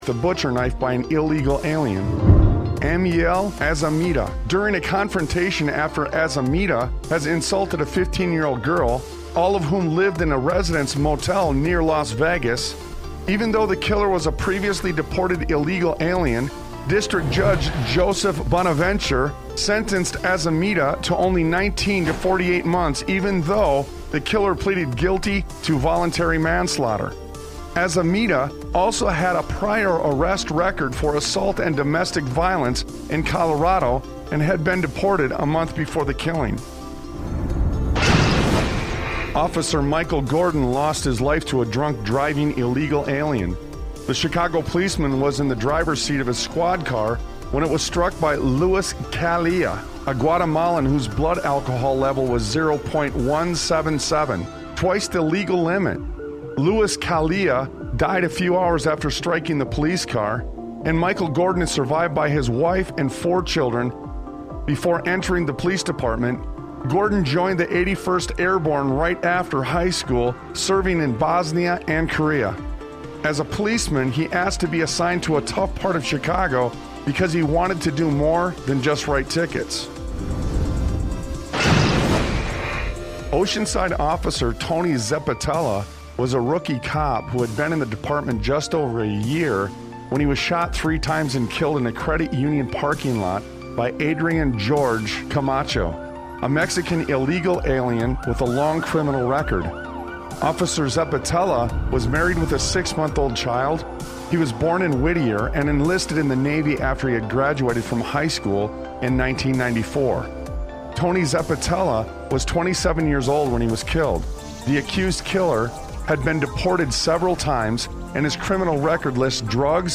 Talk Show Episode, Audio Podcast, Sons of Liberty Radio and All Eyes On The Guilty on , show guests , about All Eyes On The Guilty, categorized as Education,History,Military,News,Politics & Government,Religion,Christianity,Society and Culture,Theory & Conspiracy